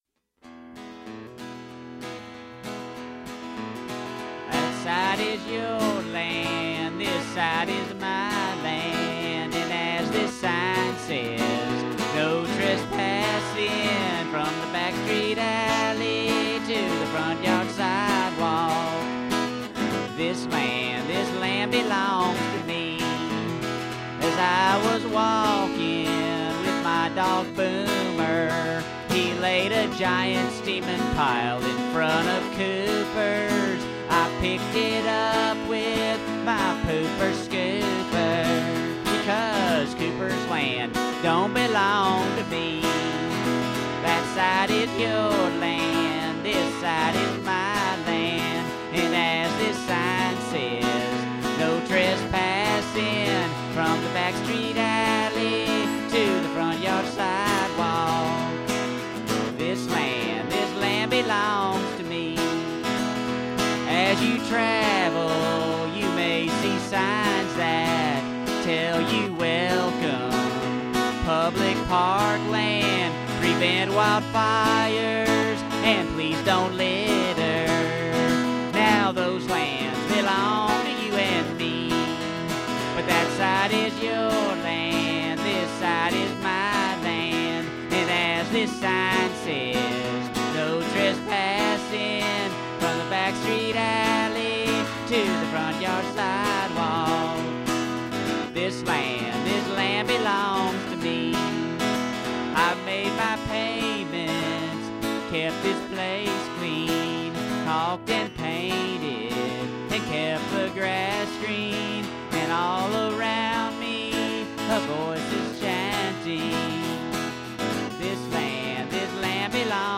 Intro:  E
(Verses have same chord progression as chorus.)